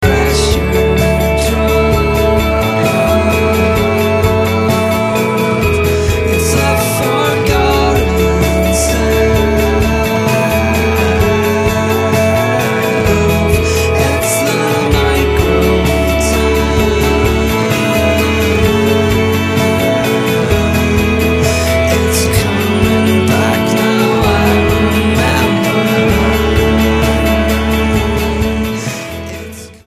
STYLE: Roots/Acoustic
FORMAT: CD Album